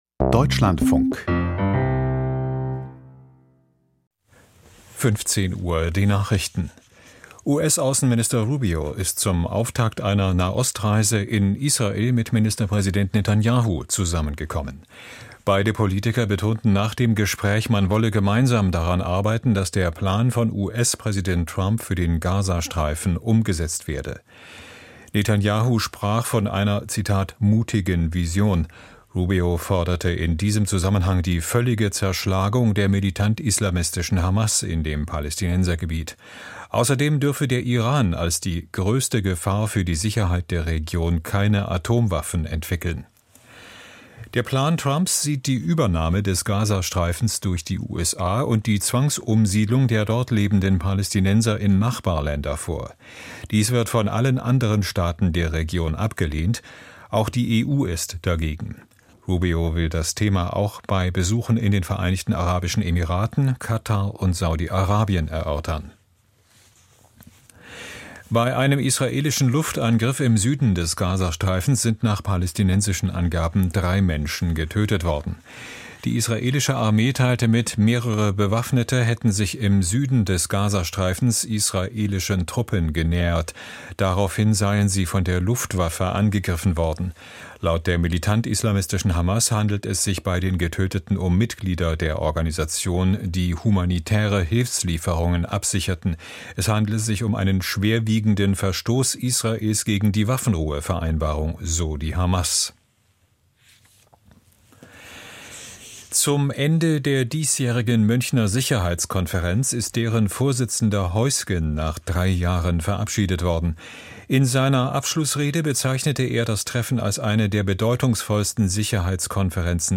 Die Nachrichten
Aus der Deutschlandfunk-Nachrichtenredaktion.